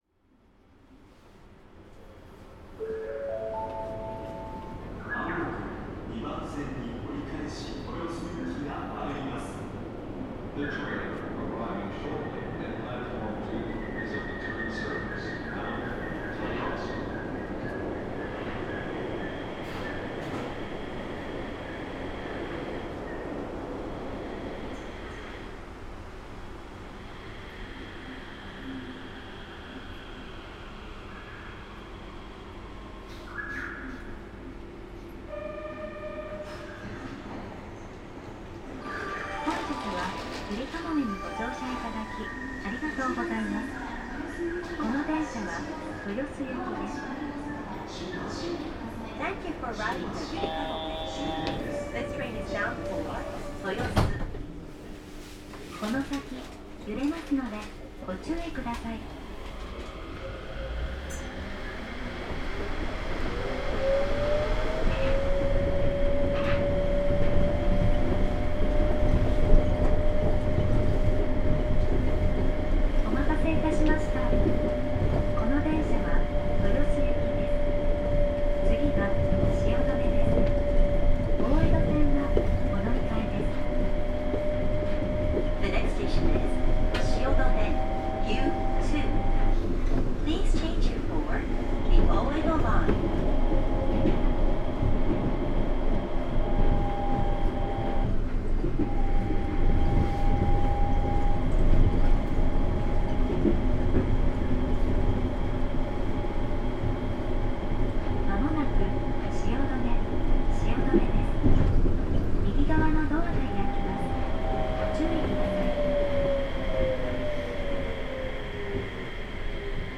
ゆりかもめ 7000系 ・ 走行音(全区間) (45.8MB*) 収録区間：新橋→豊洲 制御方式：サイリスタ位相制御 開業時より活躍する車両。